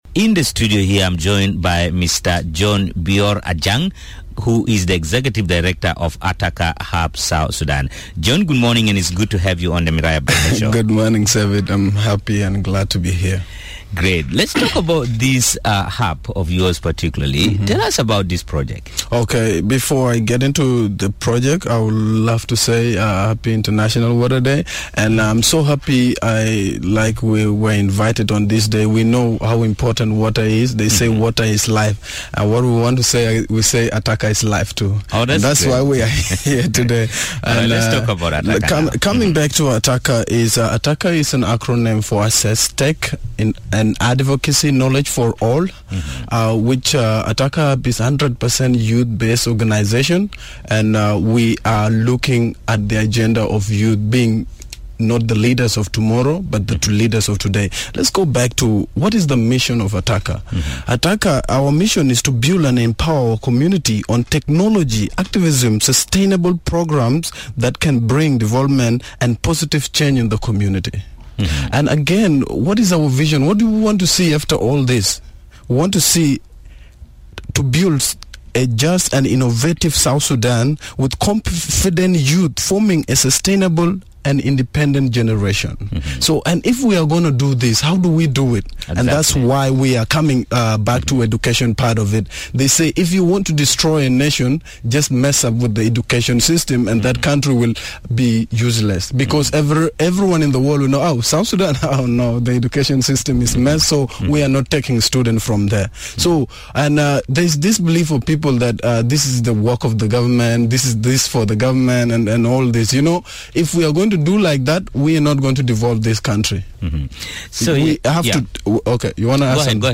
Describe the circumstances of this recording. This morning on Miraya breakfast show